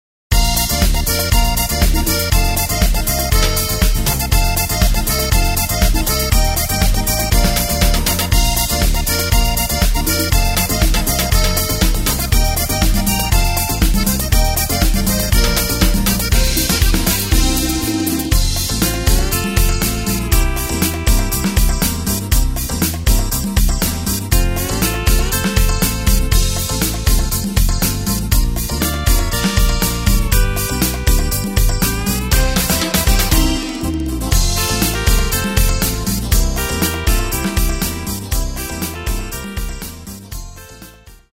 Tempo:         120.00
Tonart:            Bb
Party-Schlager aus dem Jahr 2021!
Playback mp3 Demo